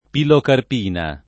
pilocarpina [ pilokarp & na ] s. f. (chim.)